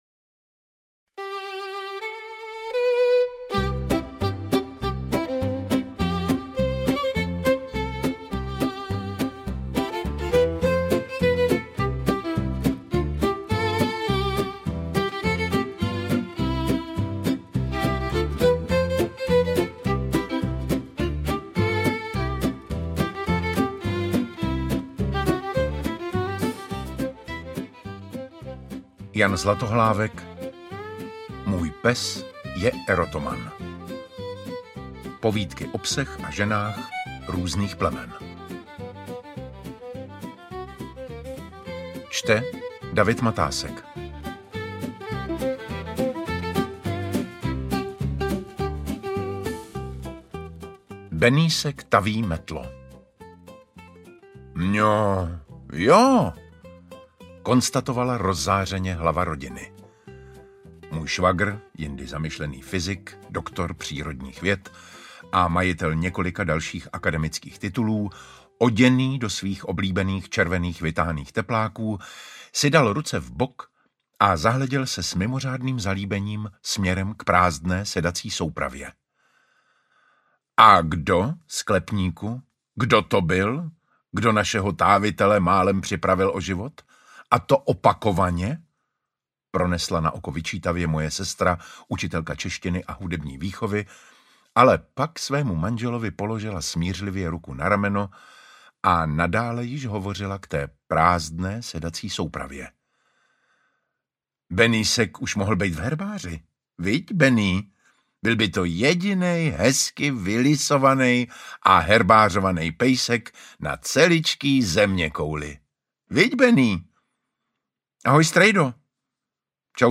Interpret:  David Matásek
AudioKniha ke stažení, 16 x mp3, délka 4 hod. 3 min., velikost 221,2 MB, česky